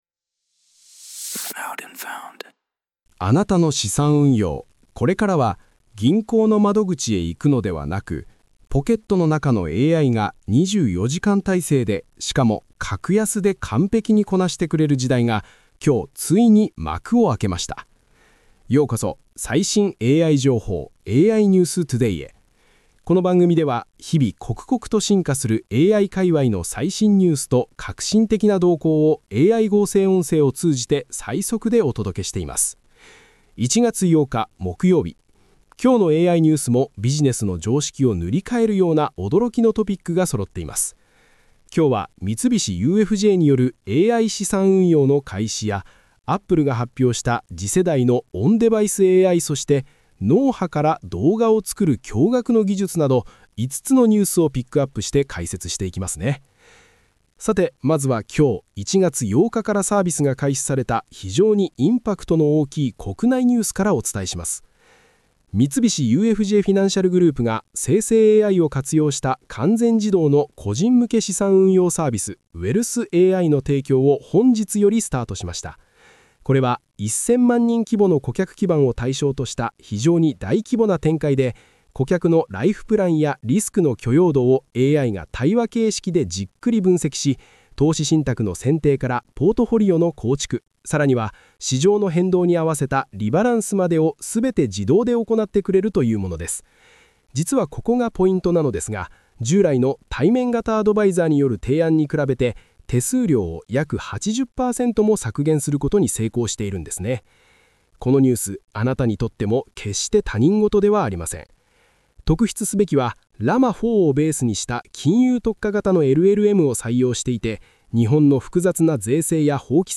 🤖 AI合成音声で最速ニュースをお届け